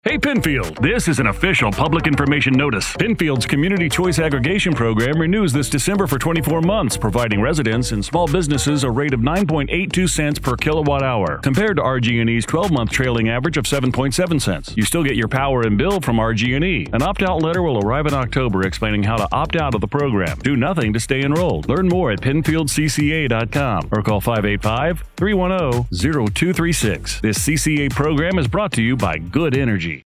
Radio Advertisement (Electronic Supplemental)
Radio advertisement promoting CCA energy program